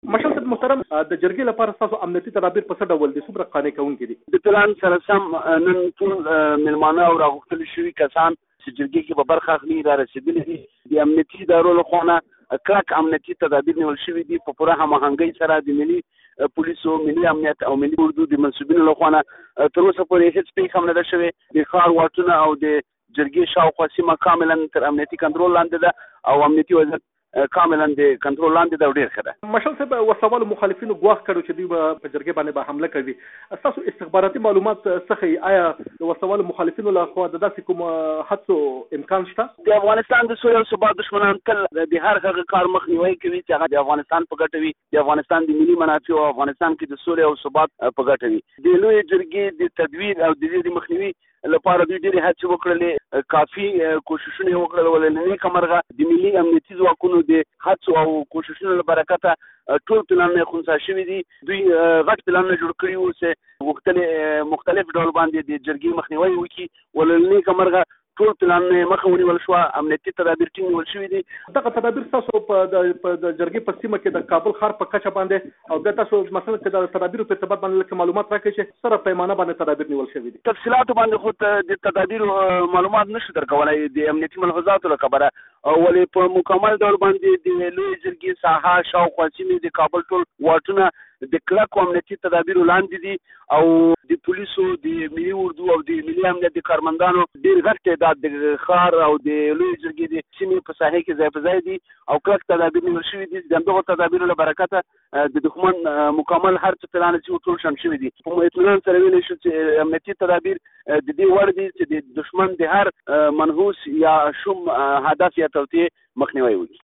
له لطف الله مشعل سره مرکه